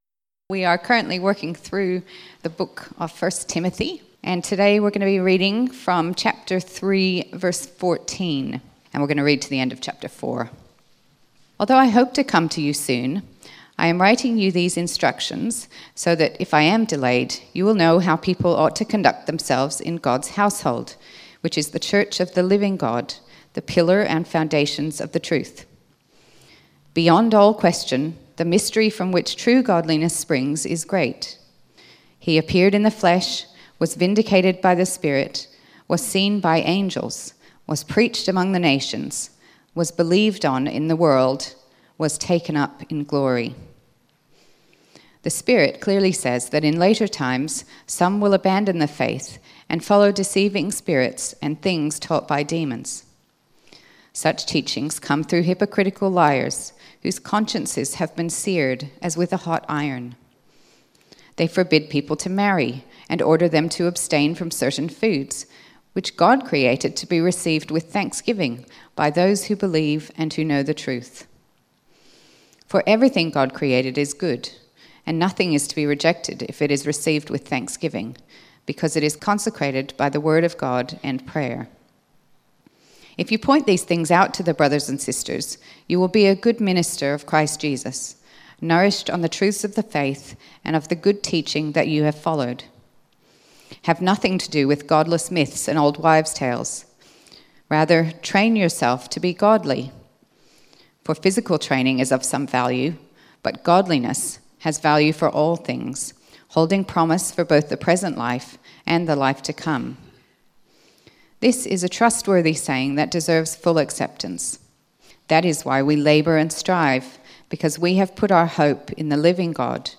1 Timothy Sermon outline